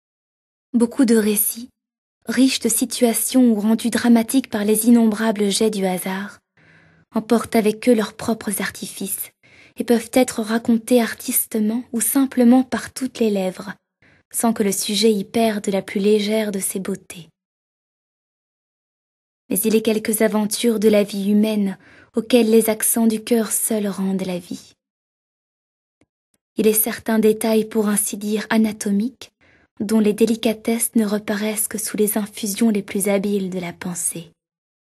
Extrait gratuit - Madame Firmiani de Honoré de Balzac